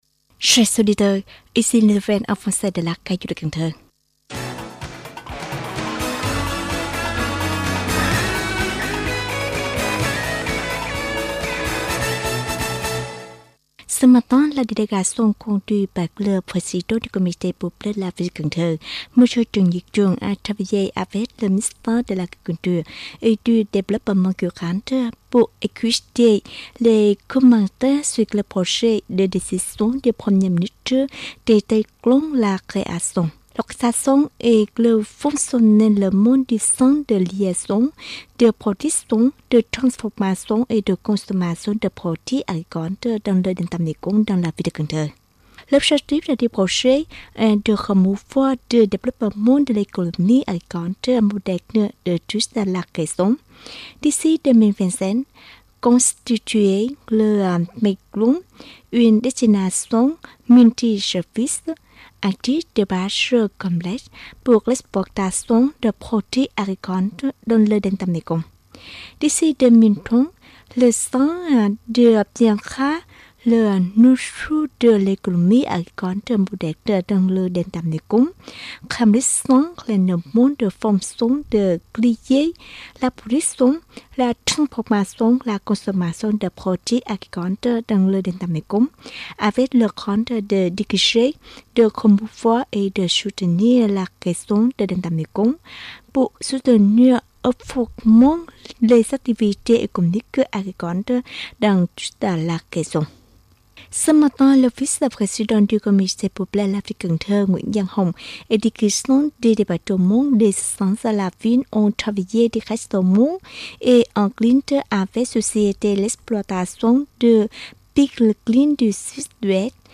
Bản tin tiếng Pháp 11/5/2022